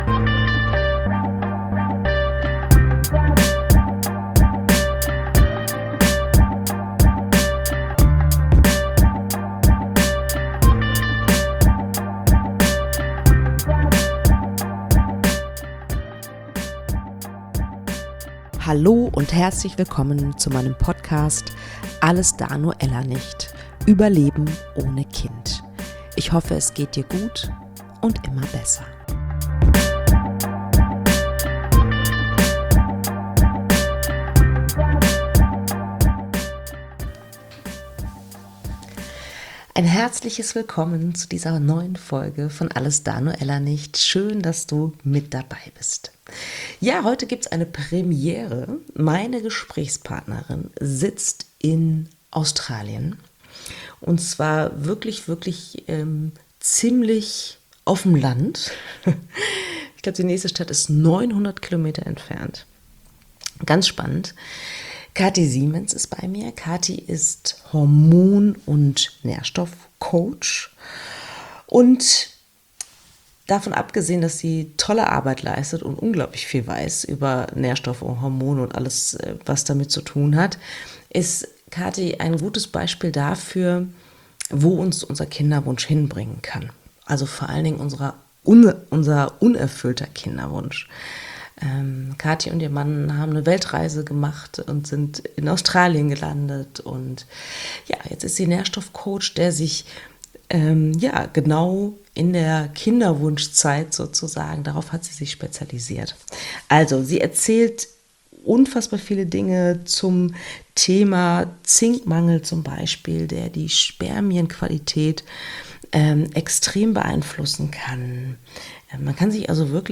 Die 100. Folge: Es ist Zeit zu feiern! Eine Solofolge, in der ich meine Geschichte erzähle